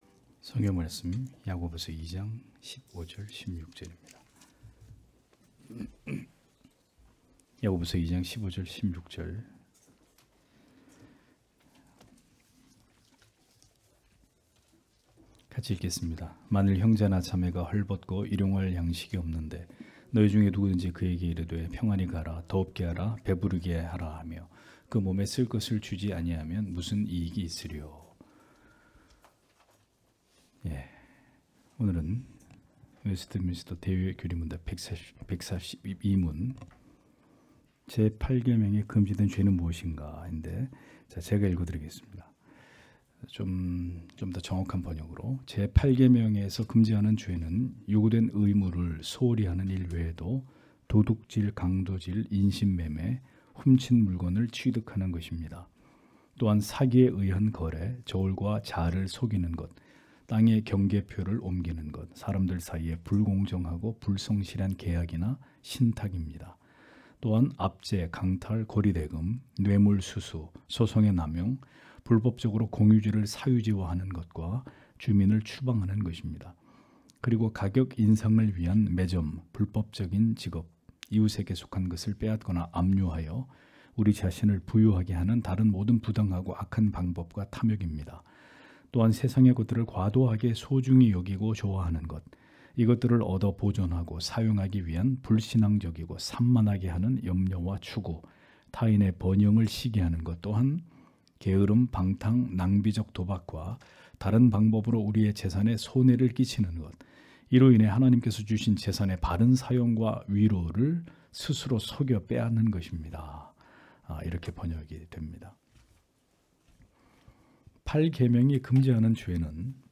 주일오후예배 - [웨스트민스터 대요리문답 해설 142] 142문) 제 8계명에 금지된 죄는 무엇인가? (약 2장 15-16절)